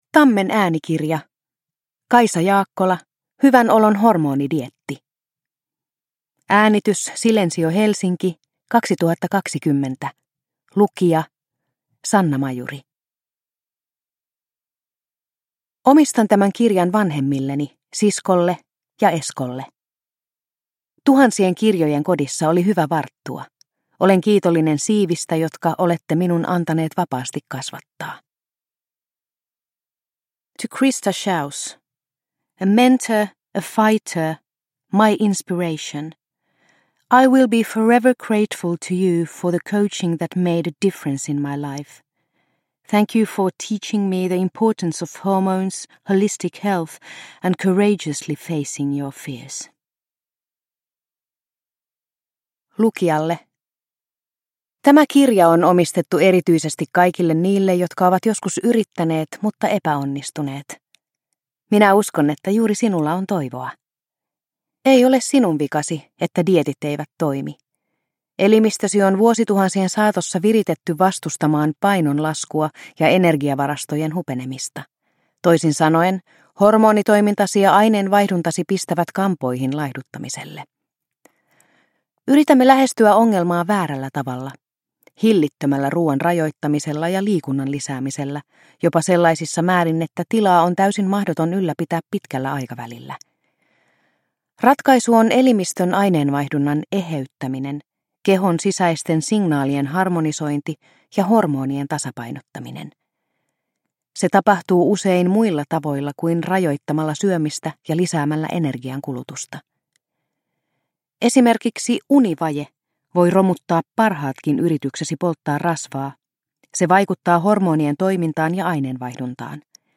Hyvän olon hormonidieetti – Ljudbok – Laddas ner